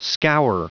Prononciation du mot scour en anglais (fichier audio)
Prononciation du mot : scour